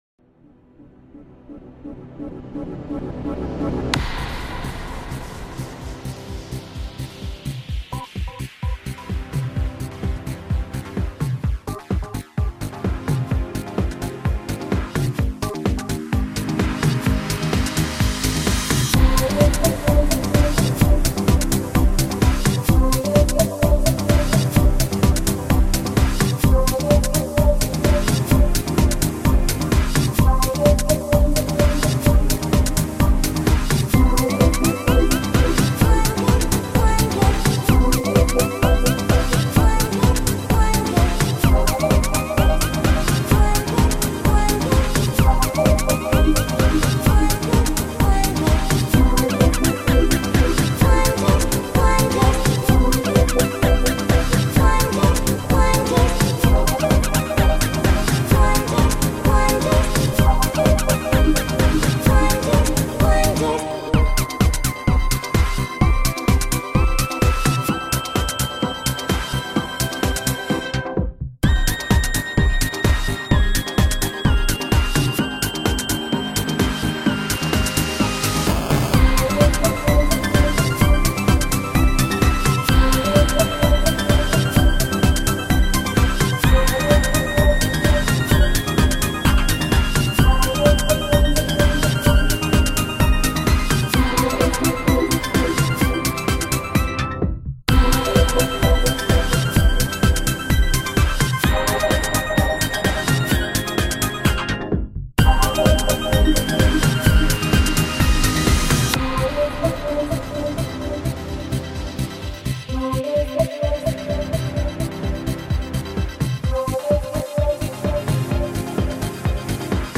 Insofar as it uses some vocals from it.
genre:remix